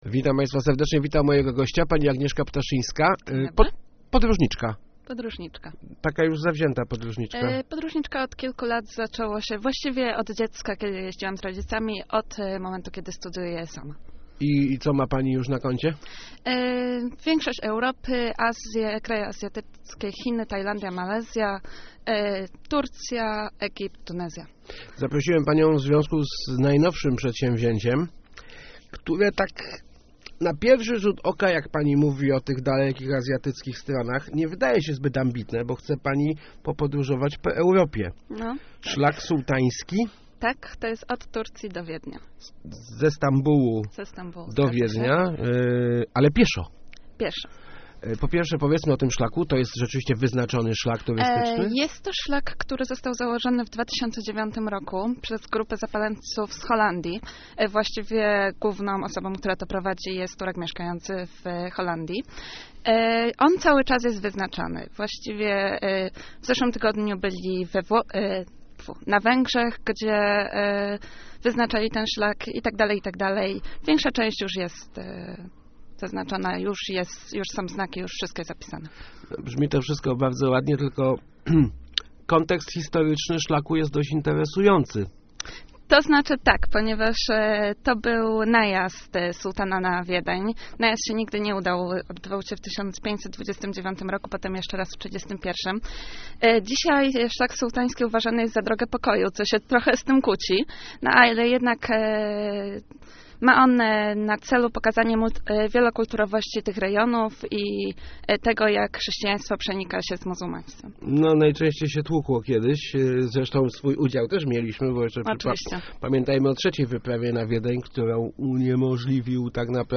-To zupełnie nowa trasa, tak zwany "Szlak Sułtański", na pamiątkę tureckich wypraw na Europę Zachodnią - mówiła w Rozmowach Elki - dziś jest to droga pokoju, prezentująca wielokulturowość naszego kontynentu.